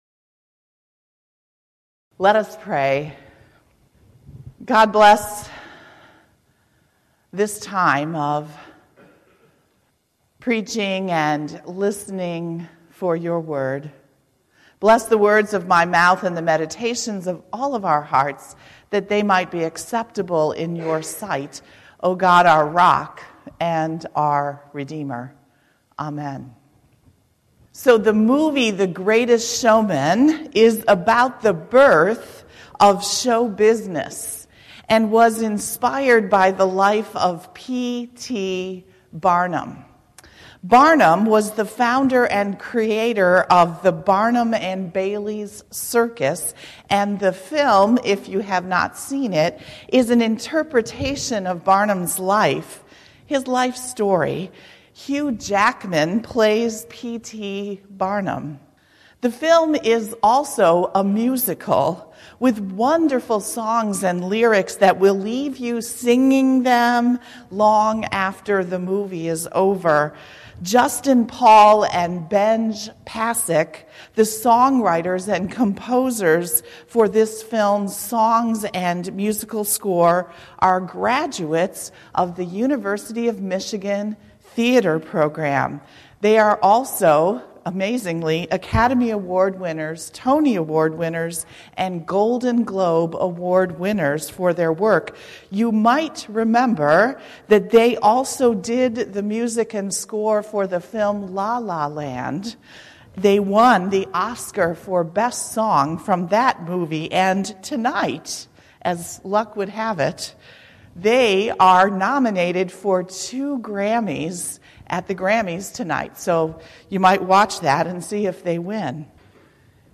2019-02-10 Sermon, “Anthem for the Misfits”
Feb1019-Sermon.mp3